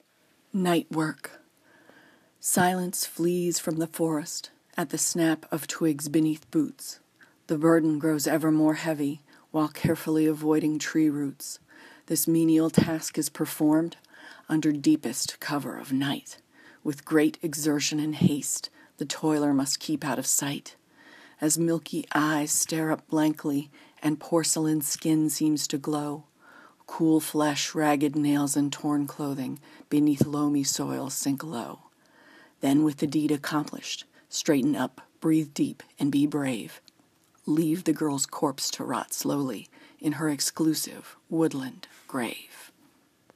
I am following now and I hope to see more of your work.